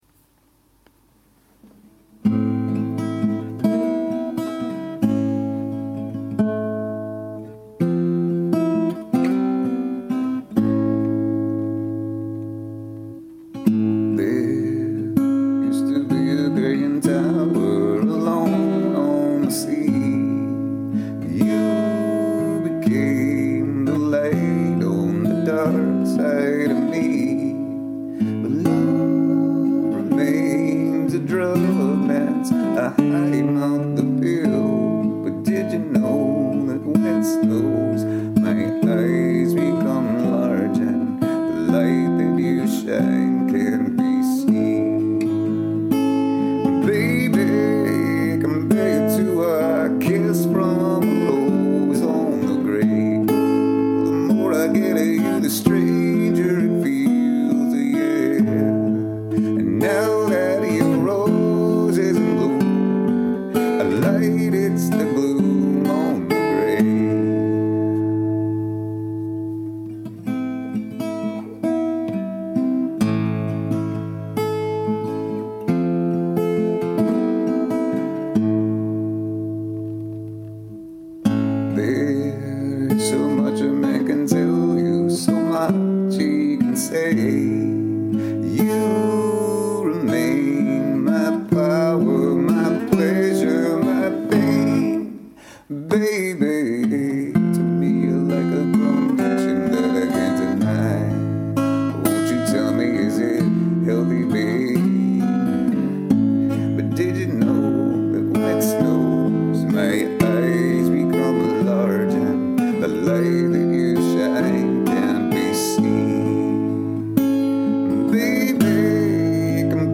(slight sore throat)